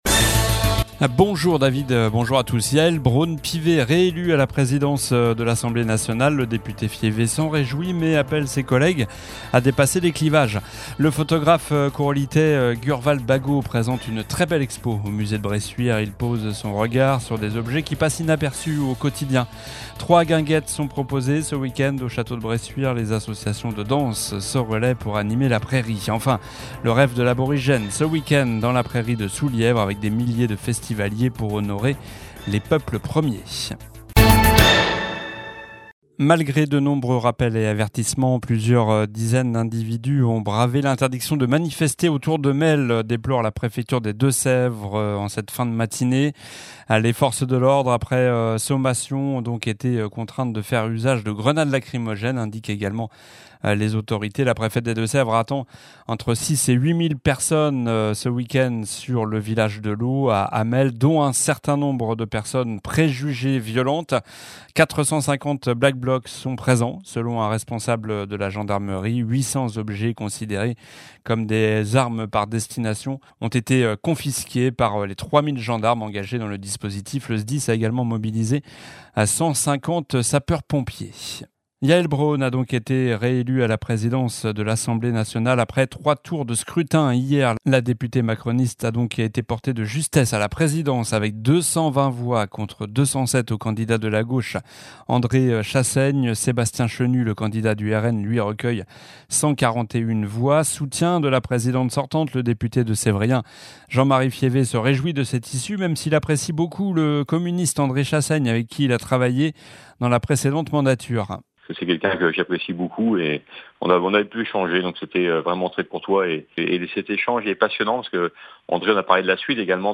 Journal du vendredi 19 juillet (midi)